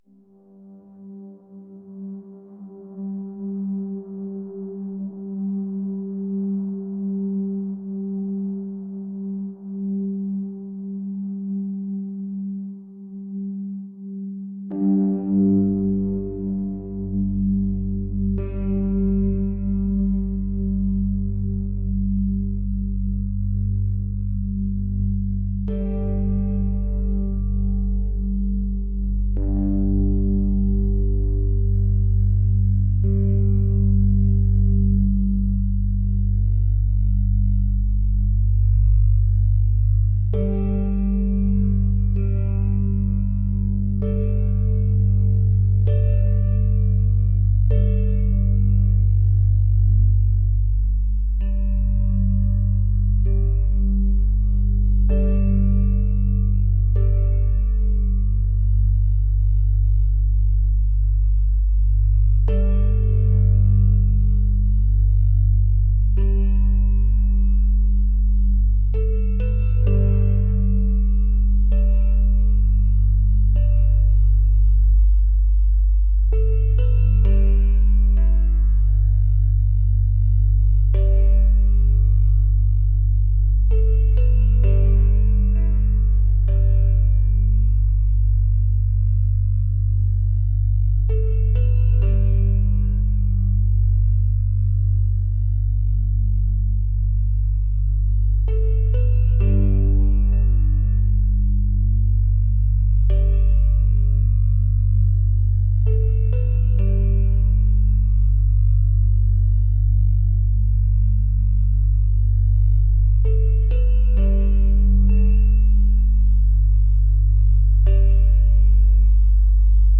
ambient | ethereal | dreamy